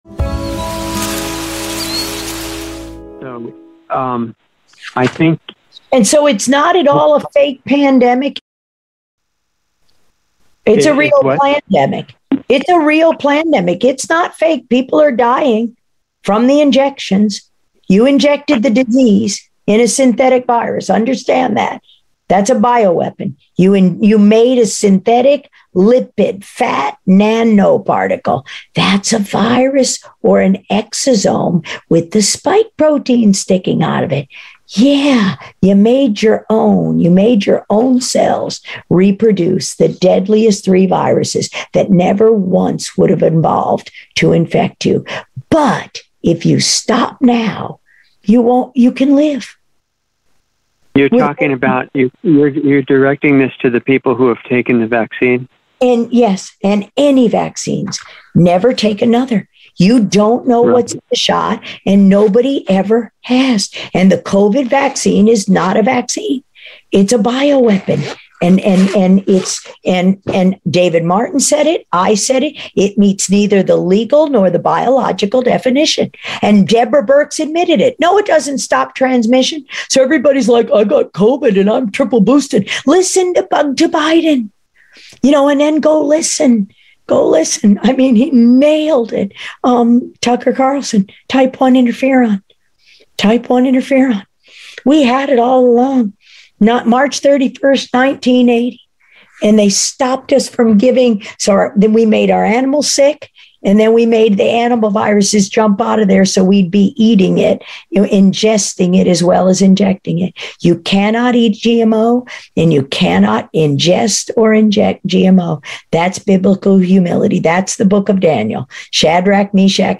Planetary Healing Club - Dr. Judy Mikovits - Insider Interview 7/27/22